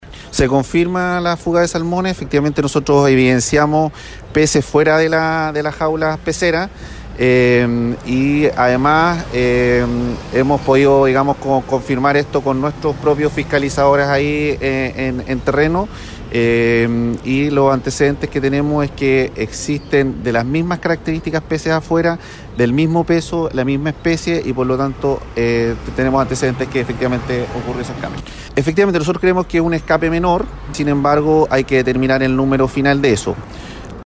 Así lo confirmó el director regional subrogante de Sernapesca, Branny Montecinos.